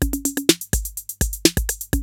TR-808 LOOP2 5.wav